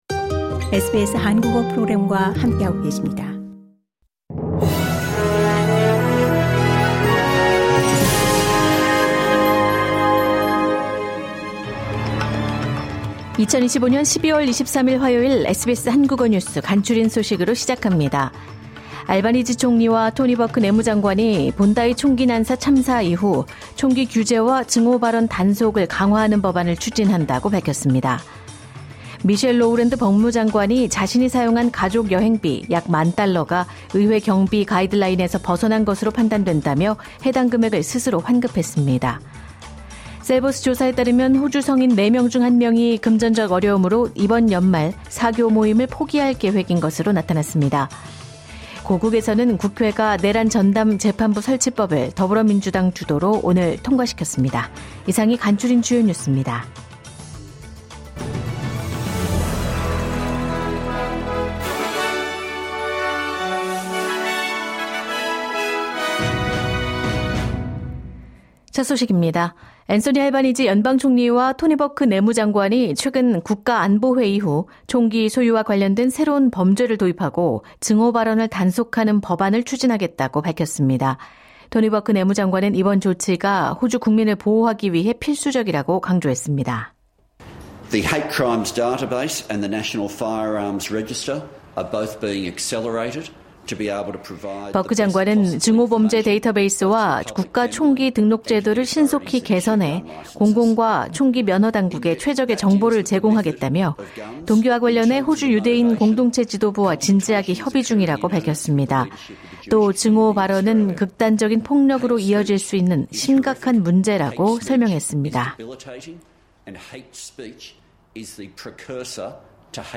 매일 10분 내로 정리하는 호주 뉴스: 12월 23일 화요일